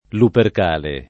DOP: Dizionario di Ortografia e Pronunzia della lingua italiana
luperk#le] agg. e s. m. o f. (stor.)